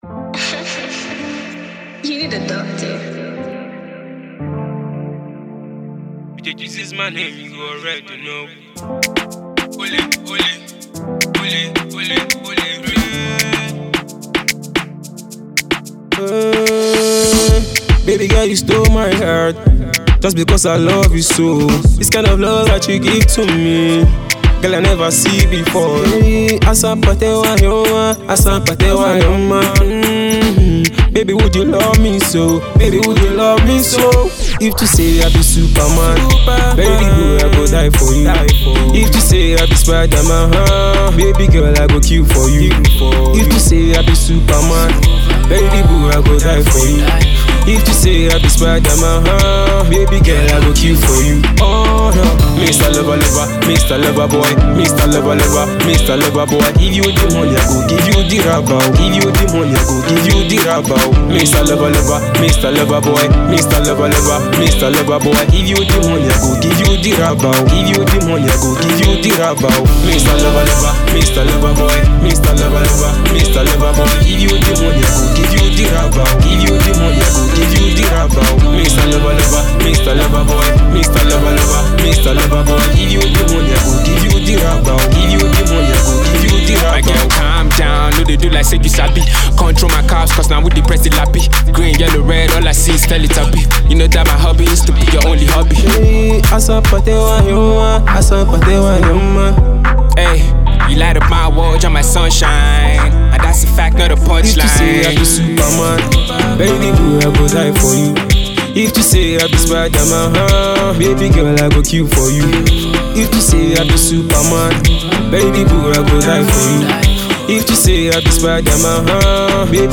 groovy love jam
Enjoy the groove.💃🏽💃🏽